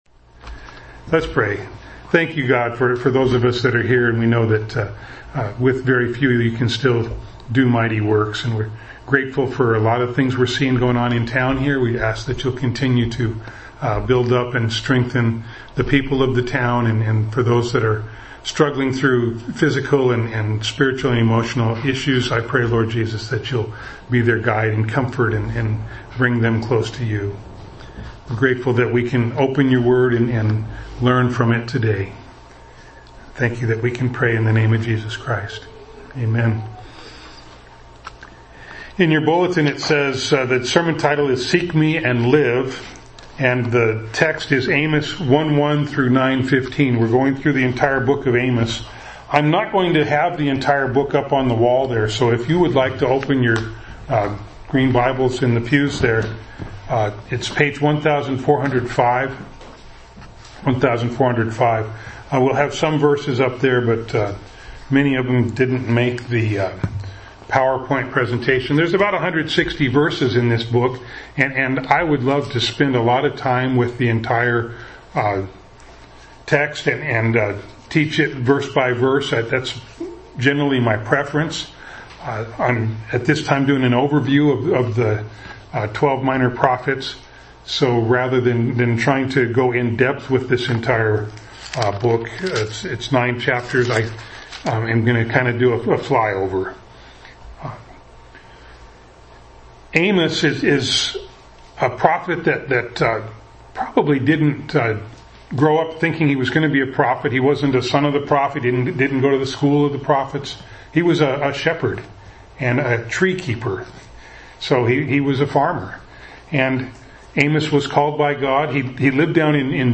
Bible Text: Amos 1:1-9:15 | Preacher: